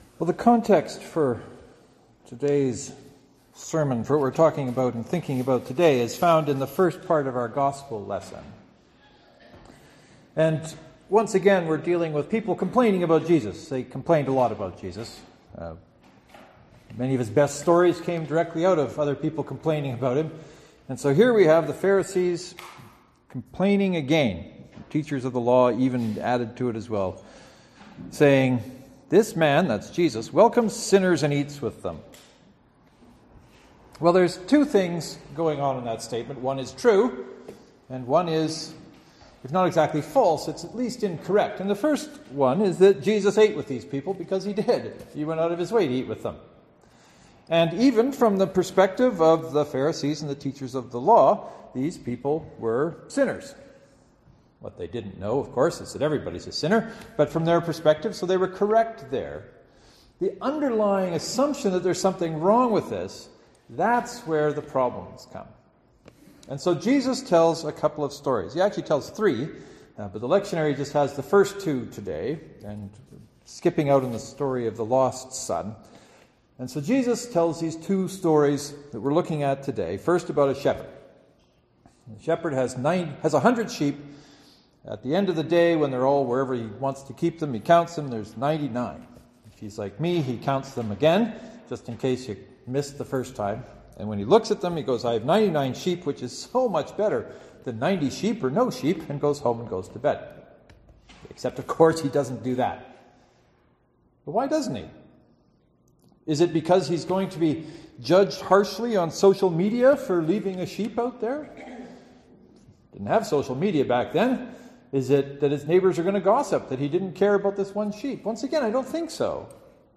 It’s a religious blog with sermons on it every week so you gotta know the answer is God.